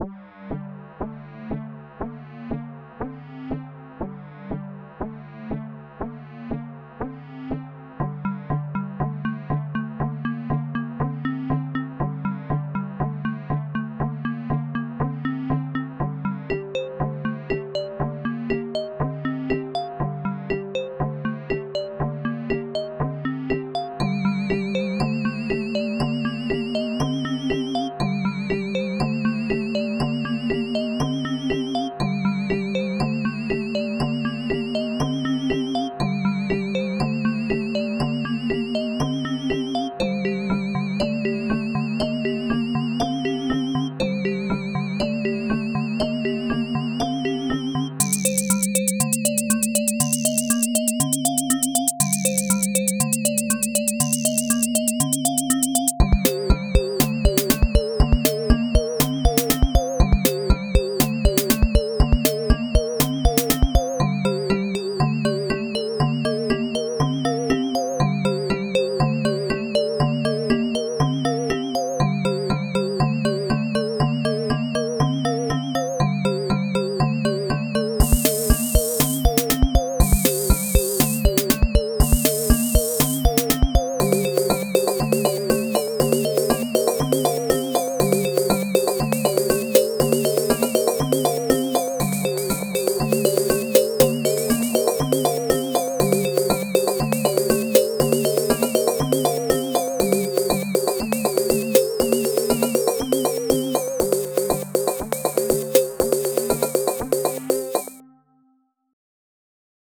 Pieza de música electrónica
Música electrónica
melodía
sintetizador